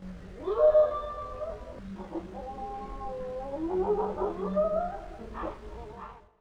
Beast_01.wav